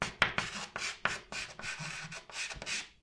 sounds_chalk_write_03.ogg